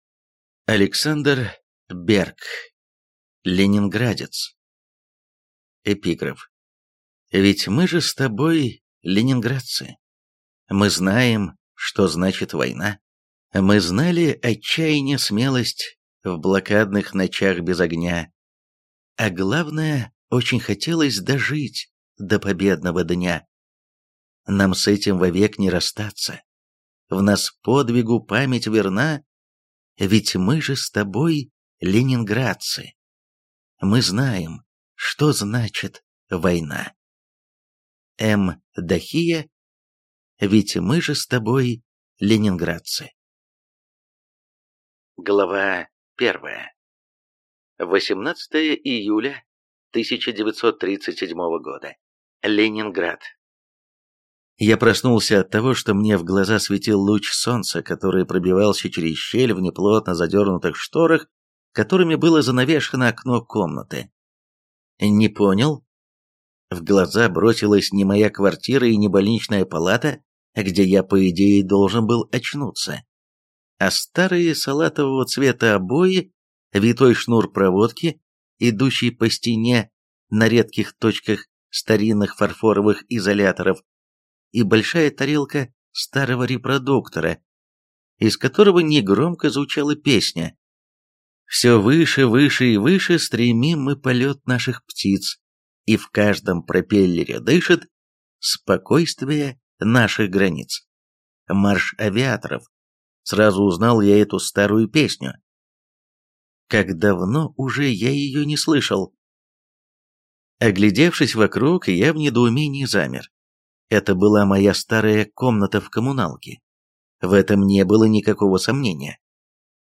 Аудиокнига Ленинградец | Библиотека аудиокниг
Прослушать и бесплатно скачать фрагмент аудиокниги